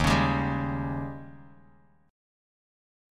D#11 chord